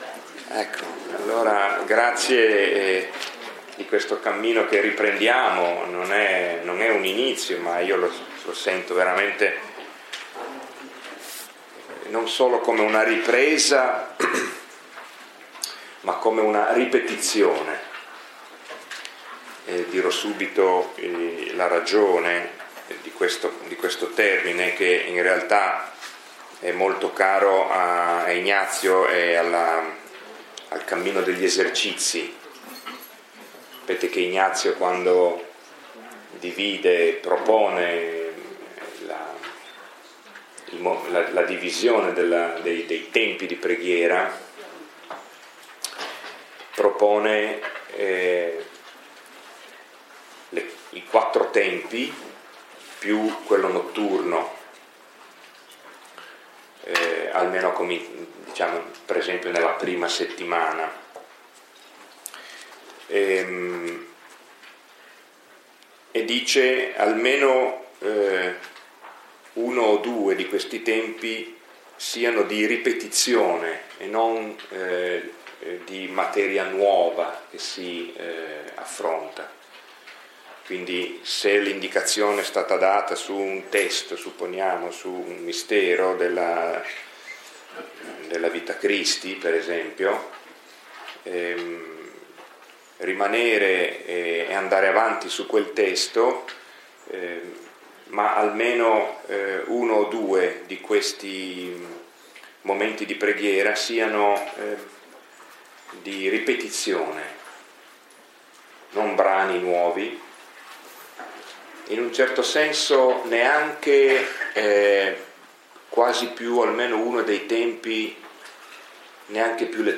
Lectio 1 – 16 ottobre 2016